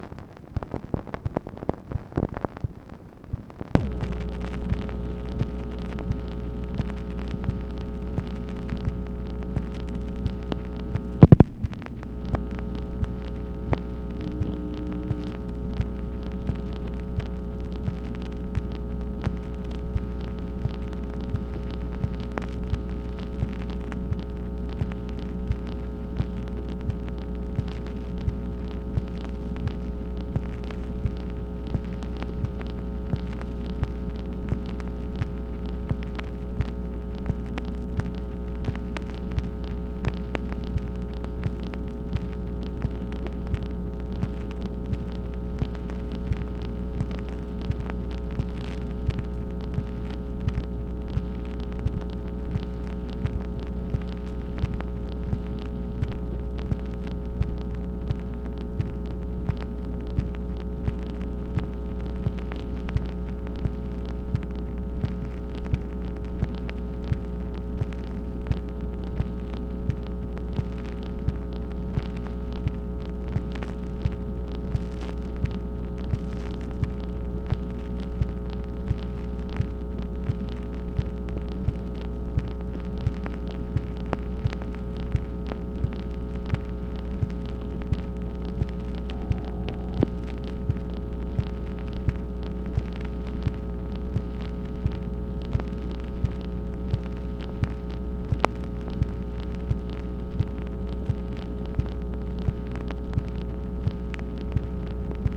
MACHINE NOISE, September 10, 1966
Secret White House Tapes | Lyndon B. Johnson Presidency